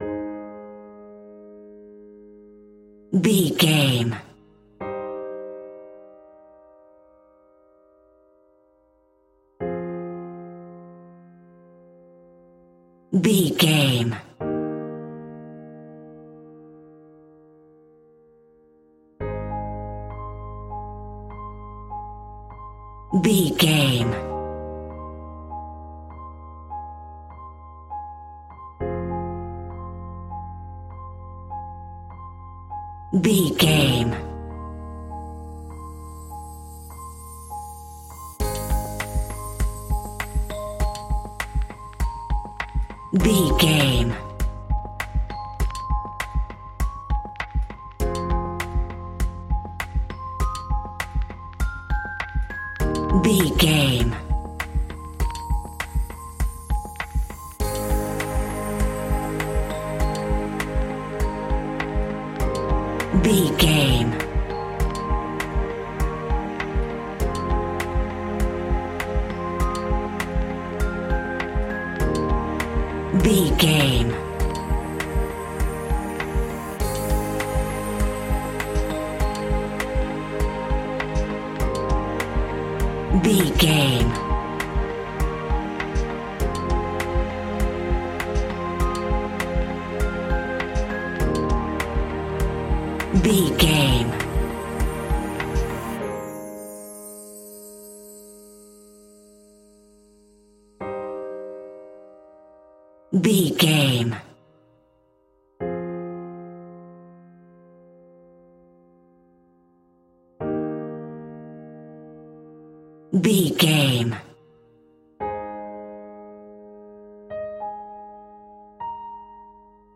Aeolian/Minor
calm
electronic
synths